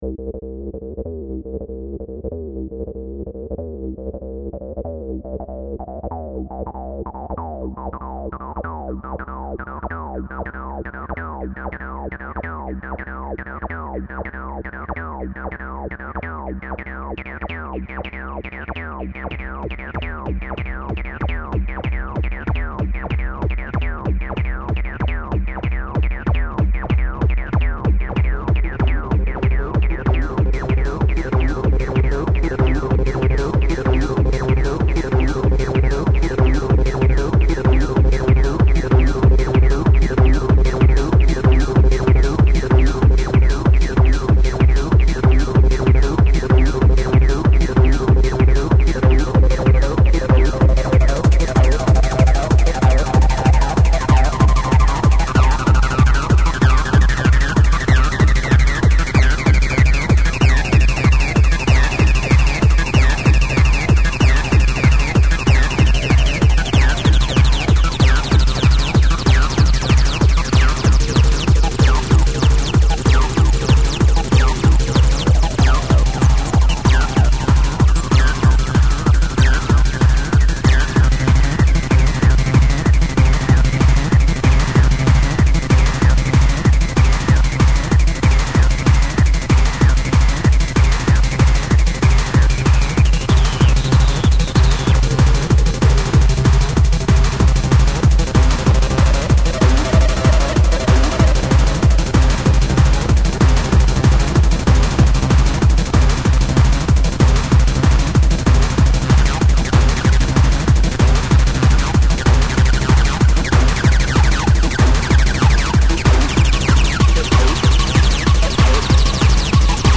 Rave Trance
190bpm